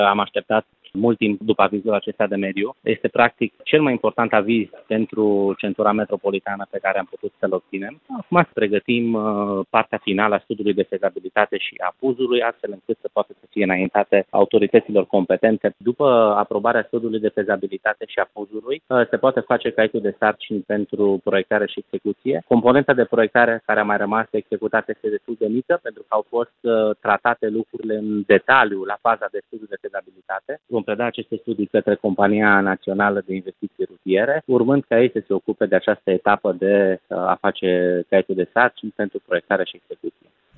Viceprimarul Dan Tarcea a spus la EBS Radio că acesta este cel mai important aviz pe care municipalitatea trebuia să îl primească. Urmează acum finalizarea studiului de fezabilitate, a mai precizat Dan Tarcea care explică ce pași mai trebuie făcuți până la execuția proiectului: